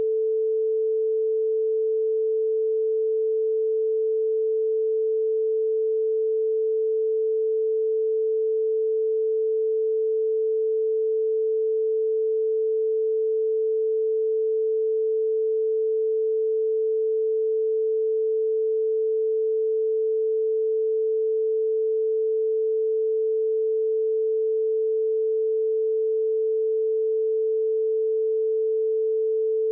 440hz.mp3